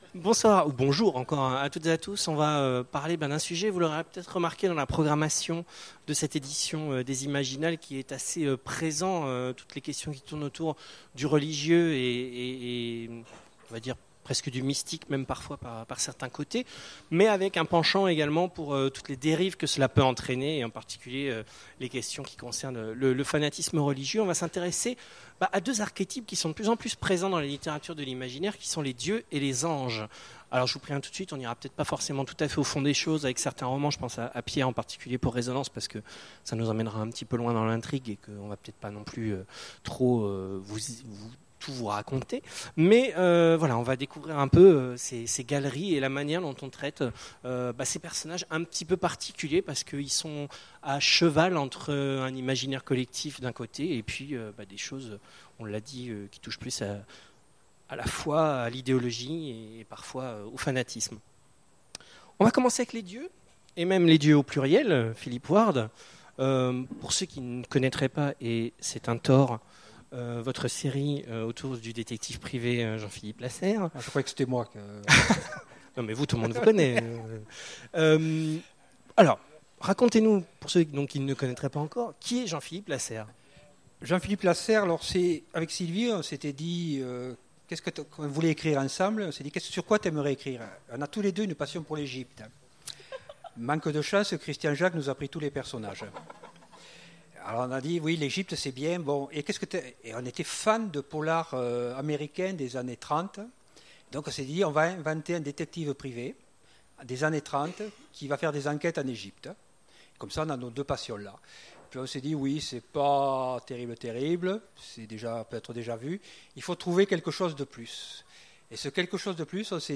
Imaginales 2016 : Conférence Anges et dieux… Religiosité ?